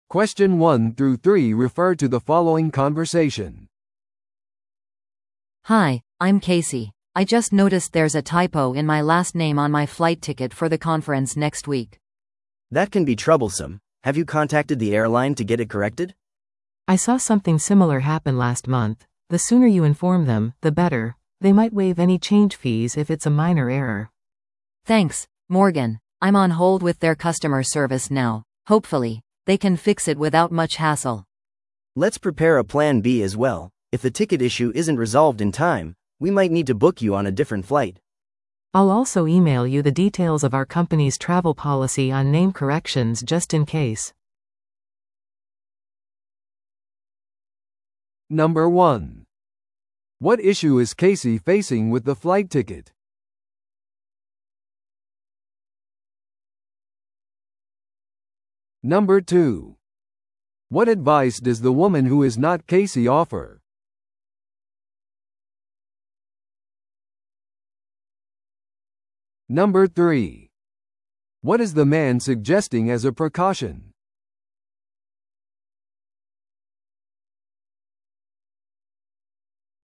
TOEICⓇ対策 Part 3｜飛行機のチケットの名前誤記について – 音声付き No.96